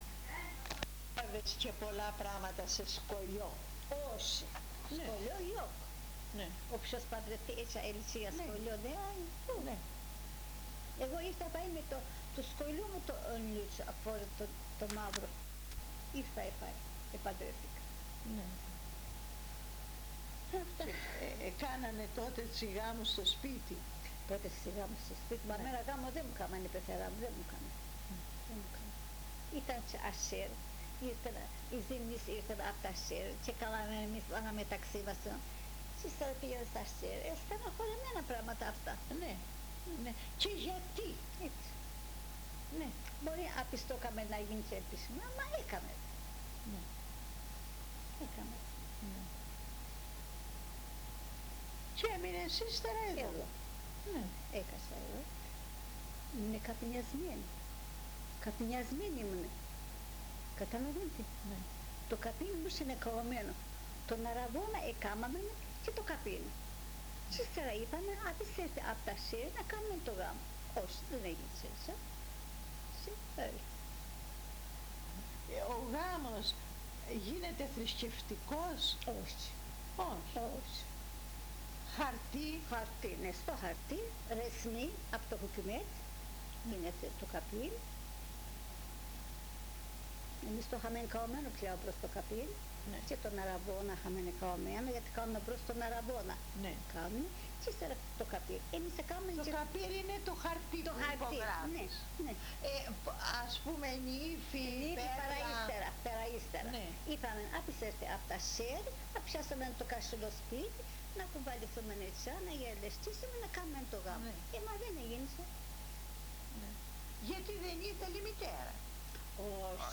συνέντευξη-5.mp3